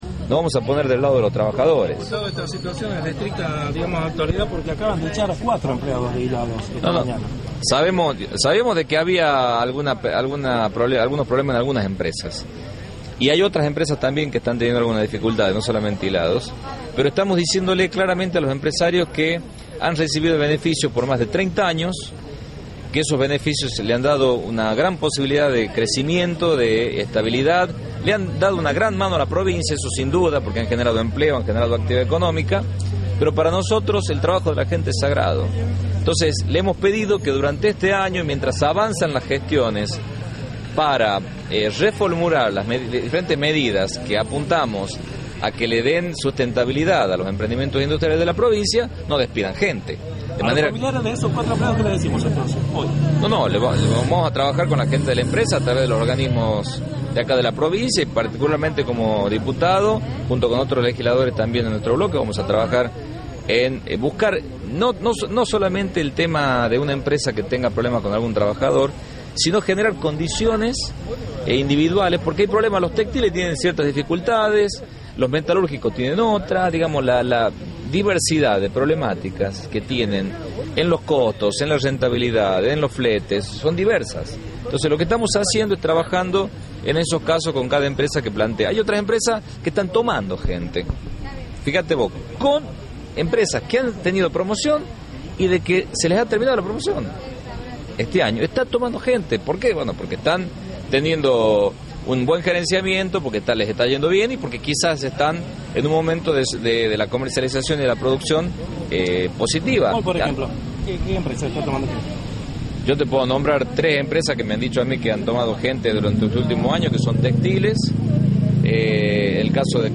Javier Tineo, diputado nacional, por Notiriojatv